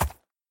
sounds / mob / horse / soft1.mp3